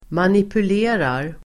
Ladda ner uttalet
Uttal: [manipul'e:rar]